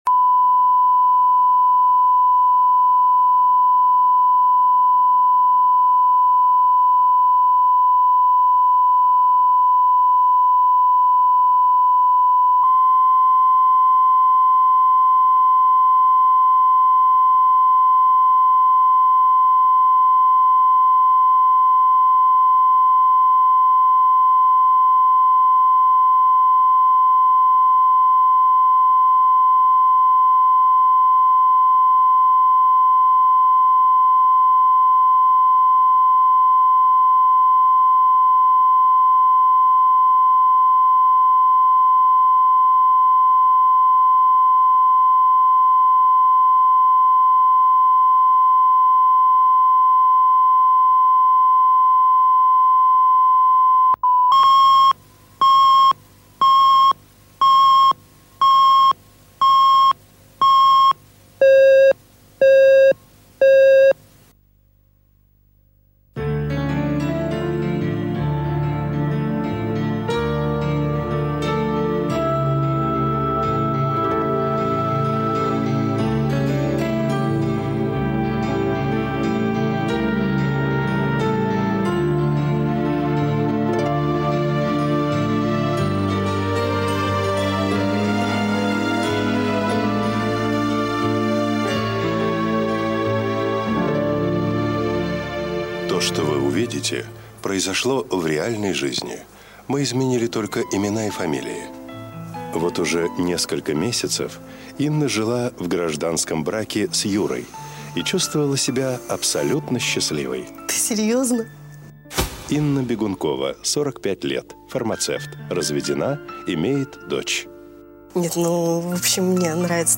Аудиокнига Молодая бабушка | Библиотека аудиокниг
Прослушать и бесплатно скачать фрагмент аудиокниги